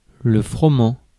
Ääntäminen
Synonyymit blé Ääntäminen France: IPA: /fʁɔ.mɑ̃/ Haettu sana löytyi näillä lähdekielillä: ranska Käännös 1. пшени́ца {f} Suku: m .